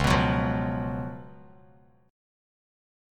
C#11 chord